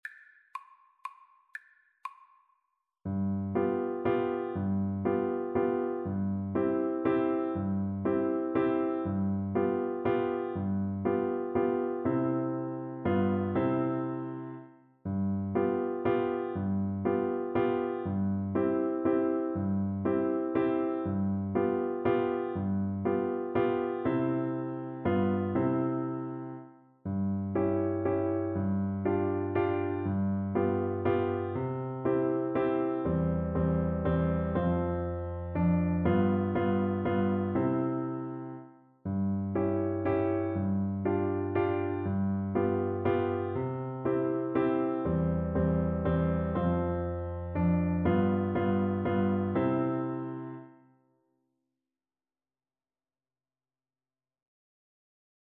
Classical Schubert, Franz German Dance, D.420, No. 2 Orchestral Percussion version
Xylophone
3/4 (View more 3/4 Music)
C major (Sounding Pitch) (View more C major Music for Percussion )
Classical (View more Classical Percussion Music)